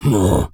Male_Grunt_Hit_07.wav